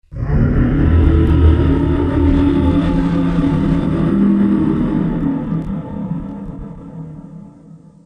Звуки дракона
Грозный рёв дракона из бездны